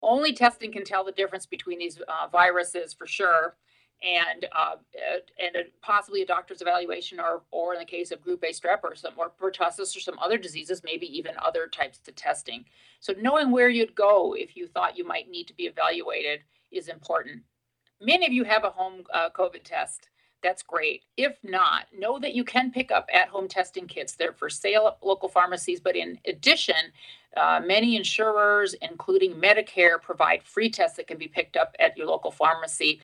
During a press availability, agency officials called the situation a ‘triple-demic’ of flu, respiratory syncytial virus (RSV), and COVID-19.